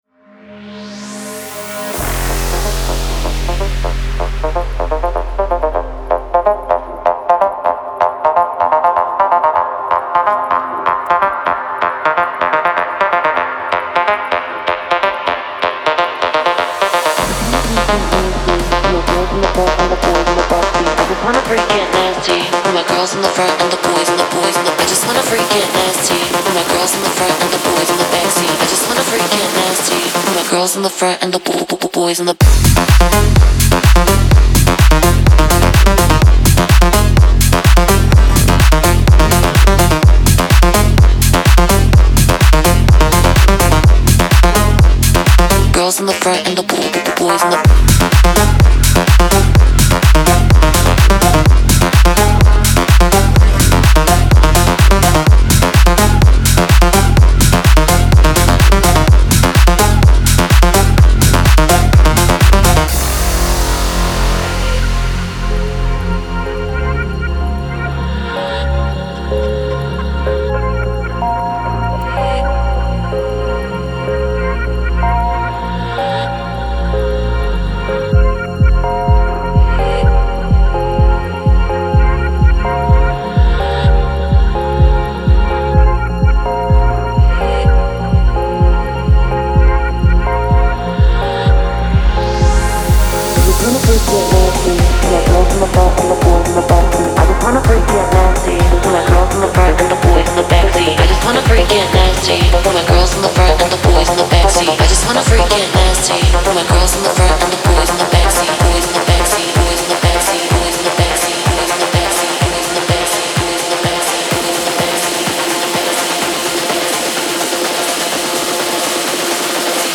• Жанр: EDM, House